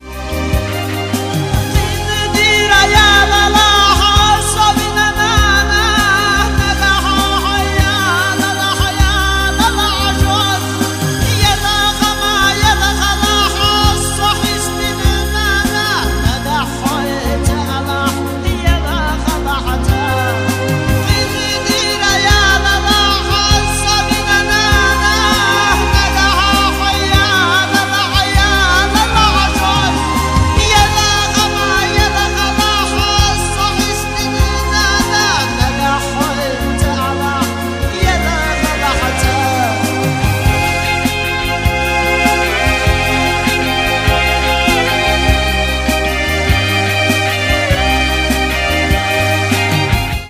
• Качество: 128, Stereo
душевные
кавказские
Душевная чеченская песня про мам.